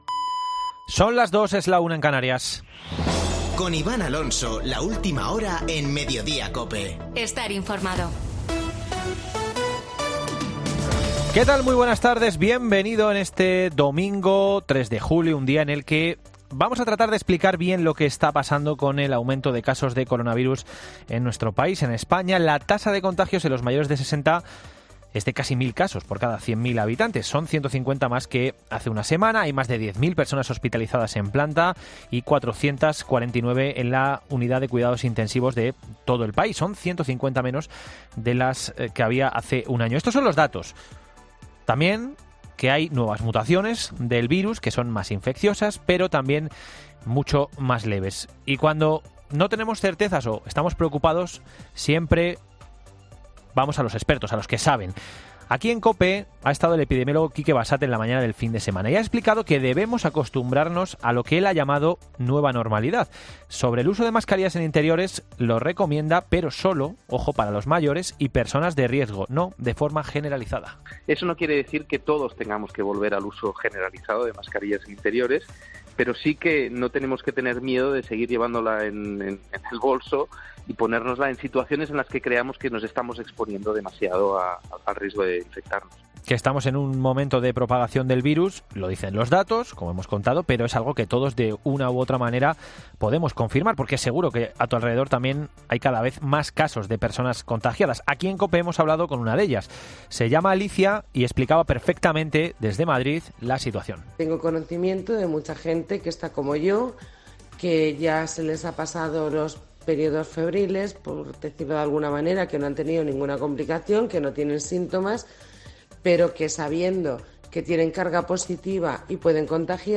Boletín de noticias de COPE del 3 de julio de 2022 a las 14.00 horas